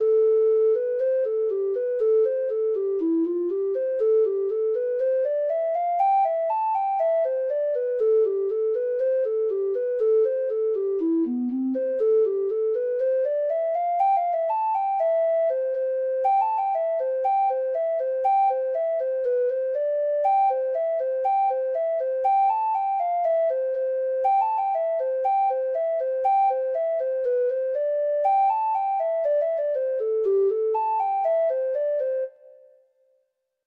Reels